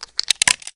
reload_shell.ogg